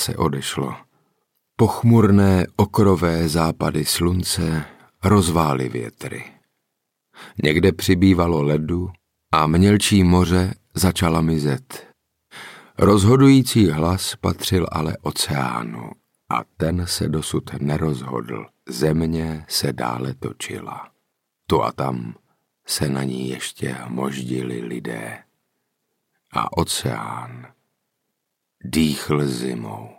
Audiobook
Read: Saša Rašilov ml.